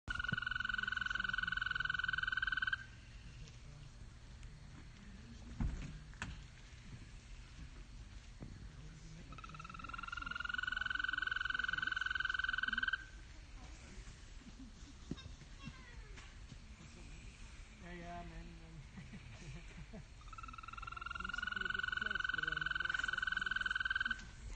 Wechselkröte
Wechselkröte am Rand des aktiven Tagebaus Welzow-Süd, gefunden im Autoscheinwerferlicht auf der Straße bei einer Exkursion am 03.05.24, ca. 22 Uhr nach einem kurzen Gewitter.
Audiodatei zur Wechselkröte am Tagebau